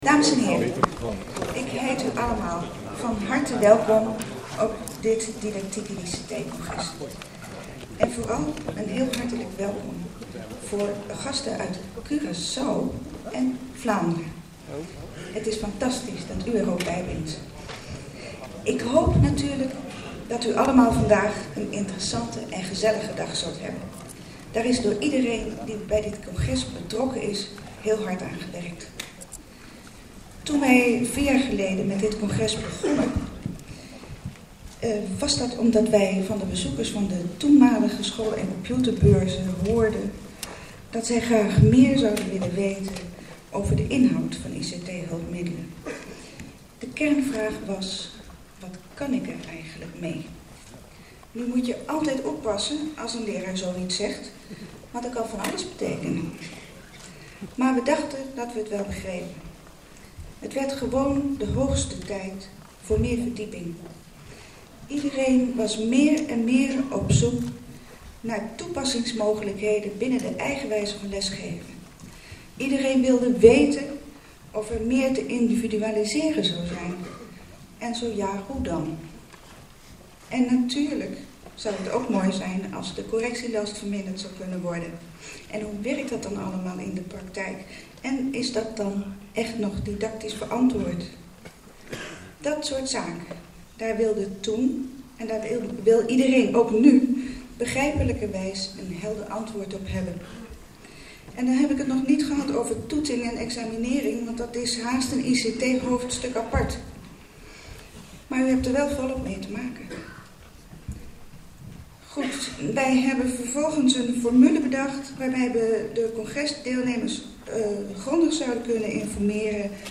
opening_school_computer_beurs_2006.mp3